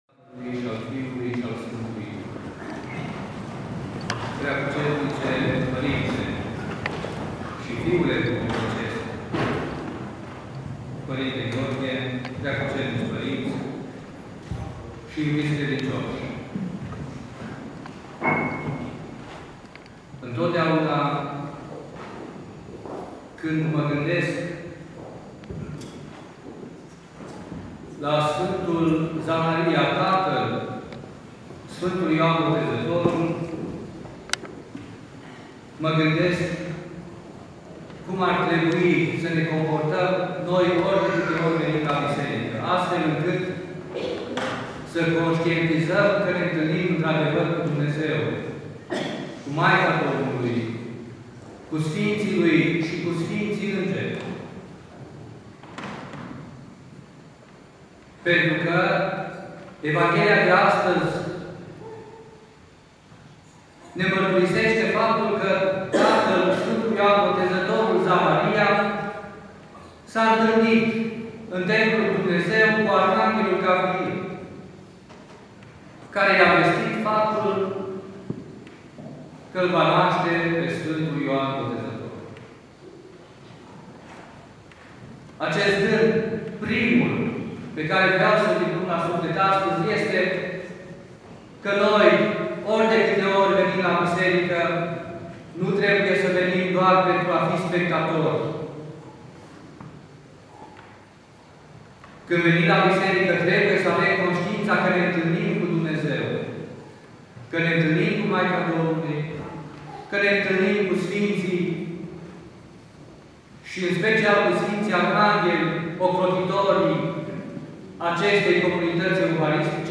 Predică la sărbătoarea Nașterii Sfântului Ioan Botezătorul
Cuvinte de învățătură Predică la sărbătoarea Nașterii Sfântului Ioan Botezătorul